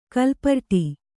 ♪ kalparṭi